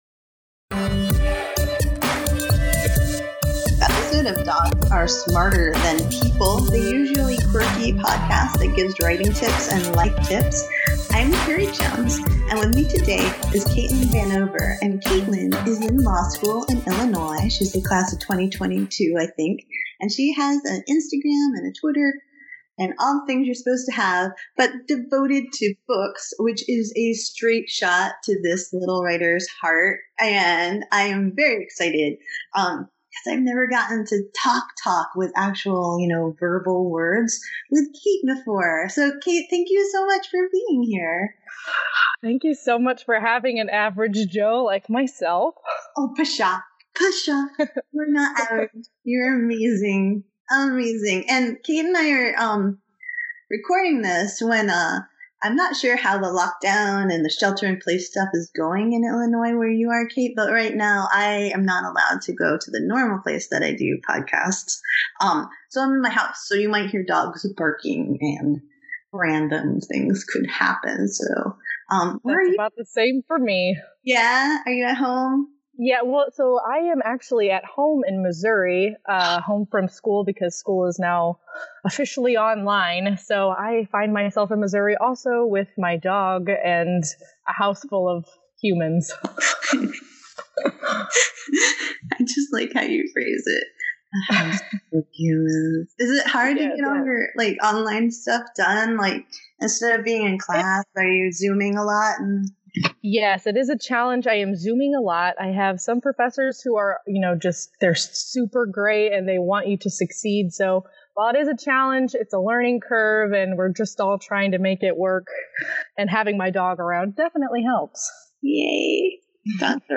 Hey! Welcome to a bonus interview episode of Dogs are Smarter Than People, the usually quirky podcast that gives writing tips and life tips.